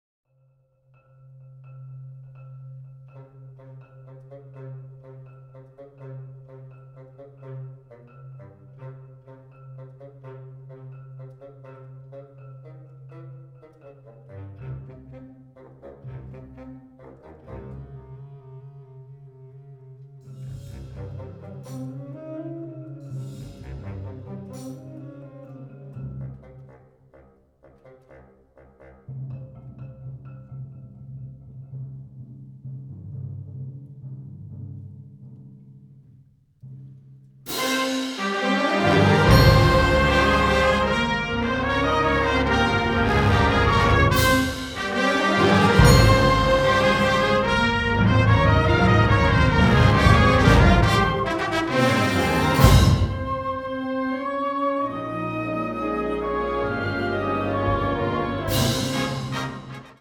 Subcategorie Hedendaagse blaasmuziek (1945-heden)
Bezetting Ha (harmonieorkest); CB (Concert Band)